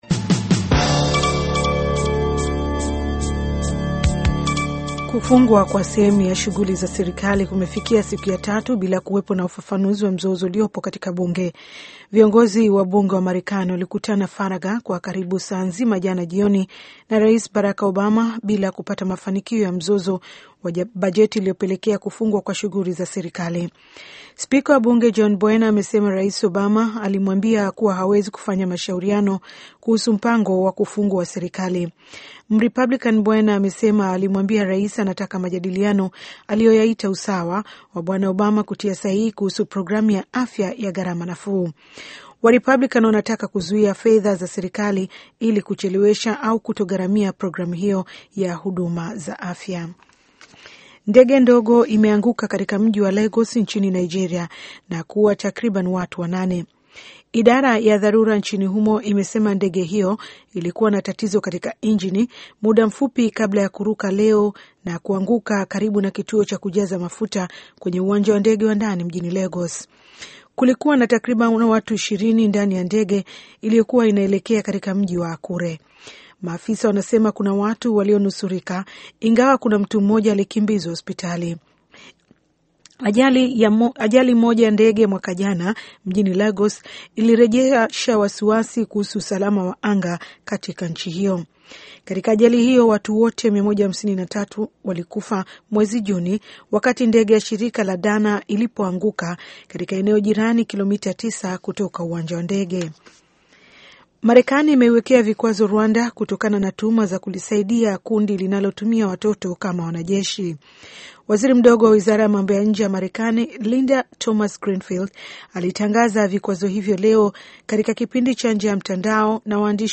Taarifa ya Habari VOA Swahili - 5:35